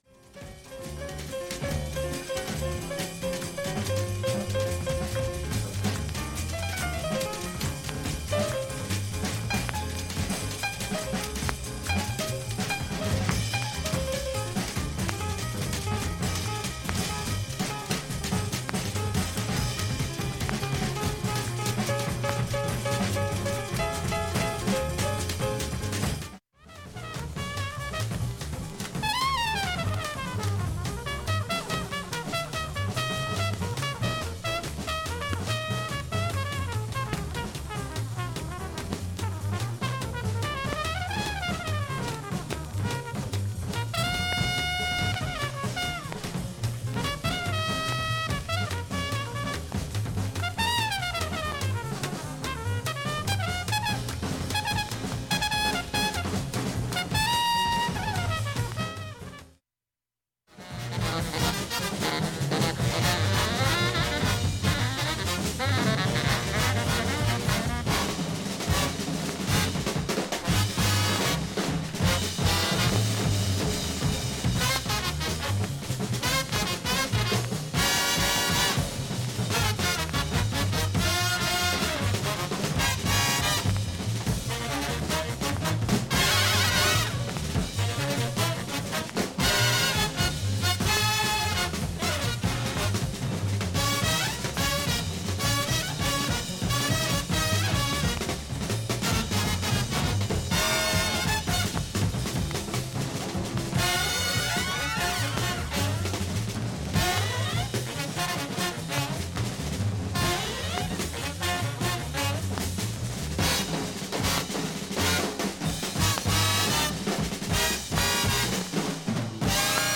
普通に聴けます全曲試聴済み。
８回までのかすかなプツが６箇所
ほか５回までのかすかなプツが５箇所
３回までのかすかなプツが１０箇所
単発のかすかなプツが６箇所